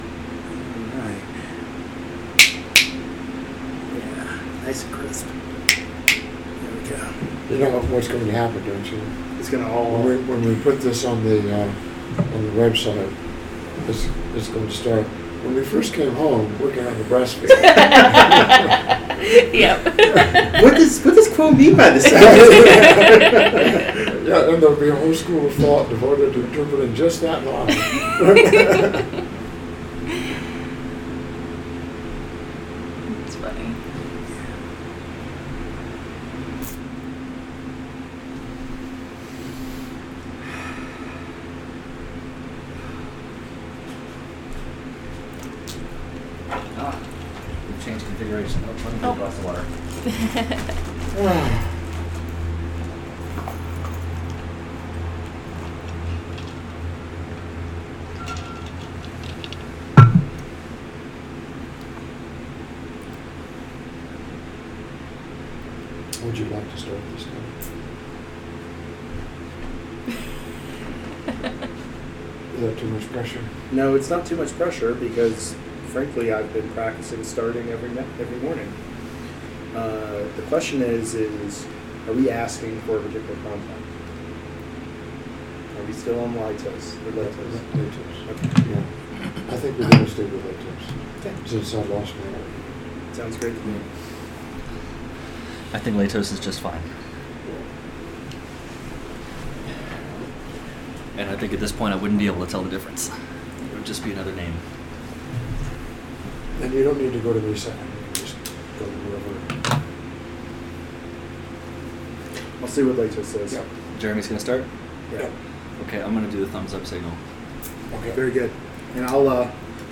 In this session from the Other Selves Working Group first channeling intensive in Colorado Springs, Laitos explores the different aspects involved in trust, from trust in self, to the extension of trust to the other self, to the more generalized trust in the Creation generally. While trust ought not be blind and wanton, for there are often dangers to balance, we learn through our disappointments how to accept others as they are, to cultivate a faith in their good intentions and highest they have to offer.